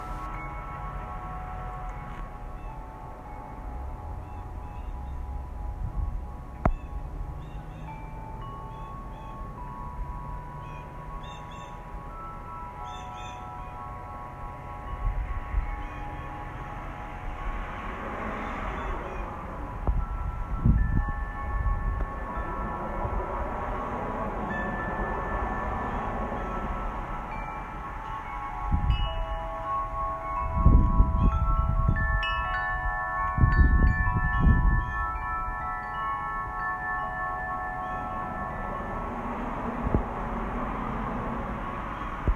I decided to record some of the sound yesterday, and share it on here.  You will also hear the sound of traffic passing by; please just ignore that and focus on the wind chimes.  Isn’t the sound beautiful??  And oh!  I almost forgot about the birds chirping!
wind-chimes.m4a